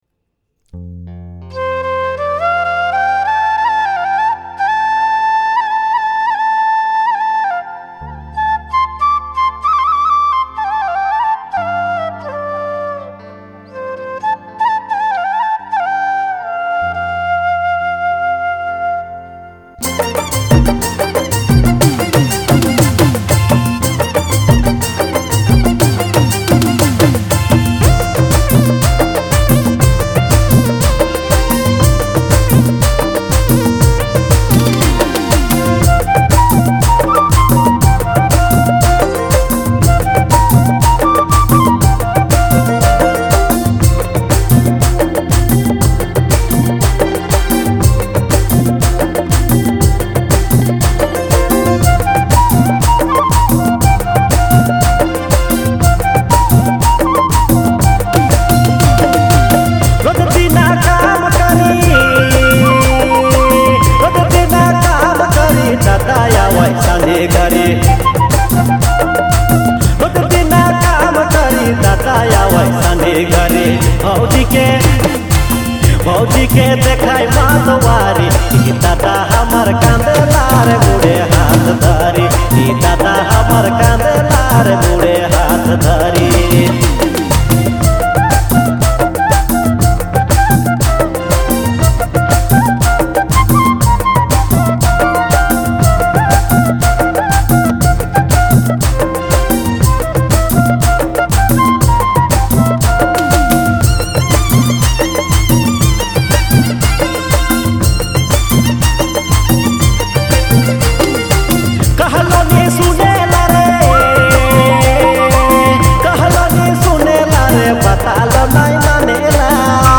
heartfelt Nagpuri song